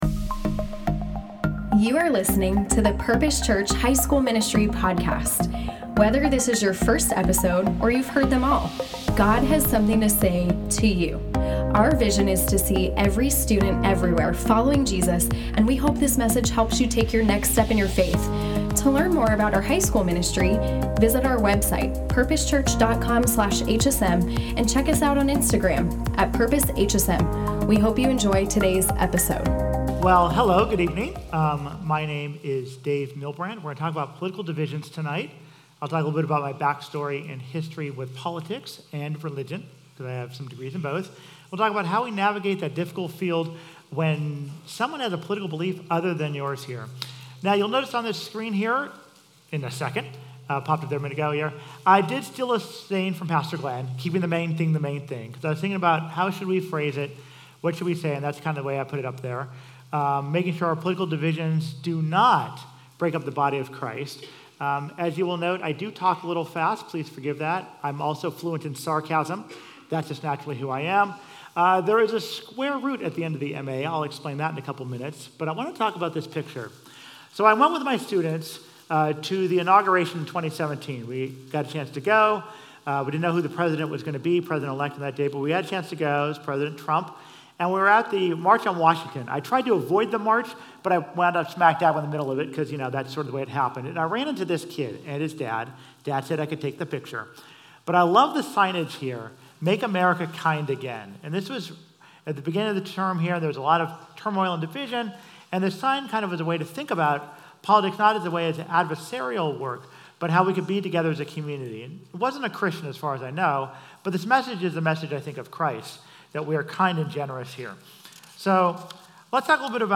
Q&A Panel | Uncharted: Crucial Conversations About Life's Biggest Storms